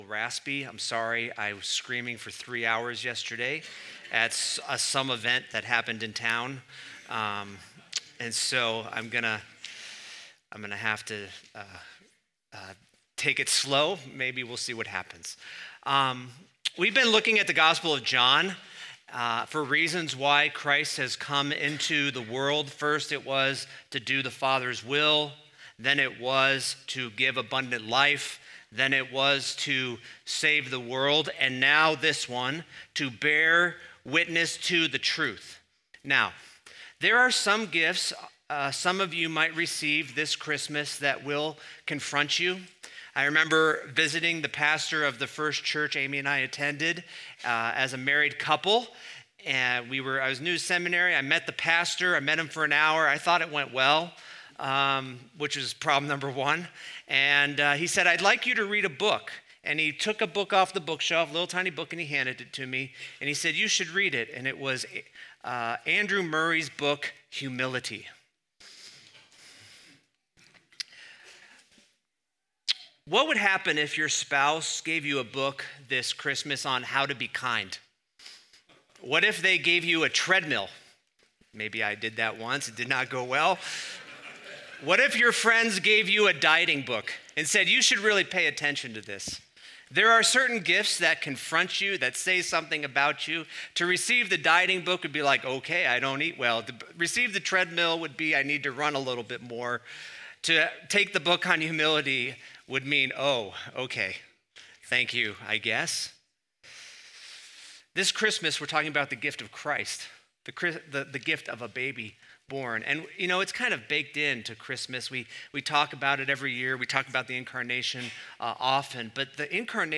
Weekly teaching audio brought to you by Redeemer Church in Bozeman, MT